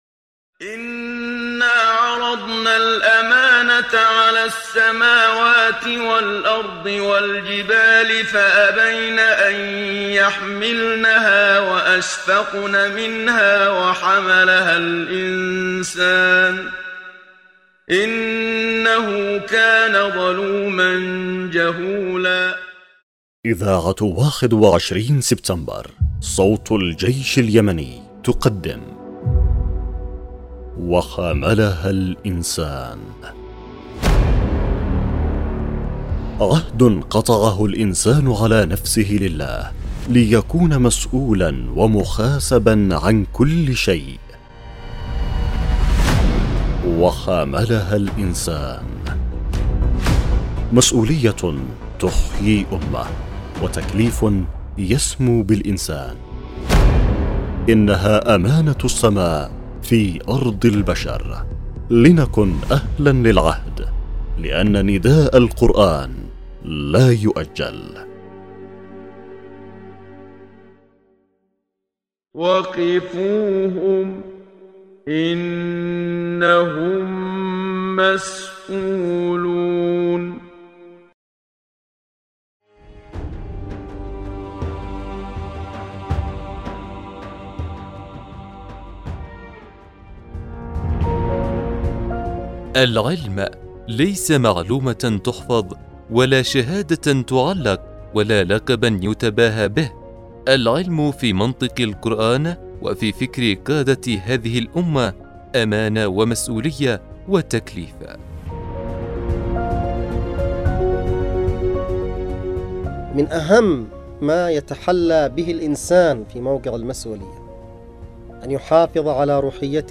الحلقة الأولى (1) برنامج إذاعي يعمل على مناقشة قضايا المسؤولية تجاه كل شيء المسؤولية تجاه الاسرة والأولاد وتجاه الدين والإسلام وتجاه المقدسات وتجاه الشهداء وكل قضية الانسان مسؤول عليها امام الله مع شرح ونقاش عن كل الجوانب التي تشملها تلك المسؤولية